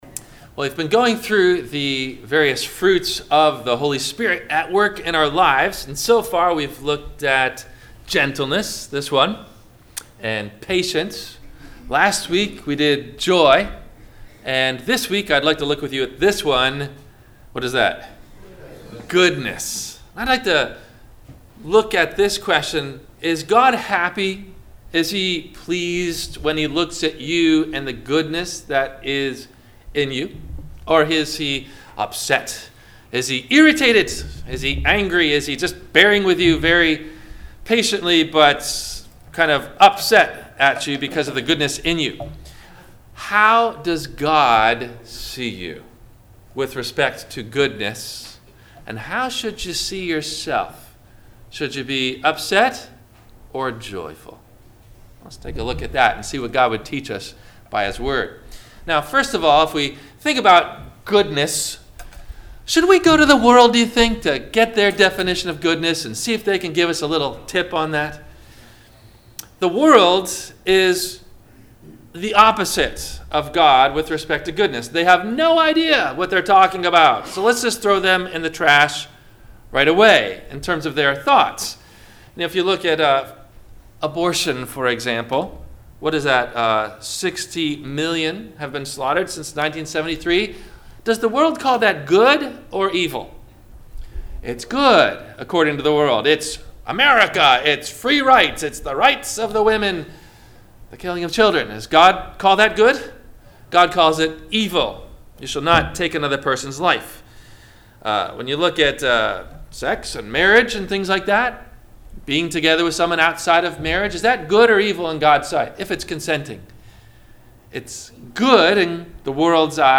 - Sermon - October 20 2019 - Christ Lutheran Cape Canaveral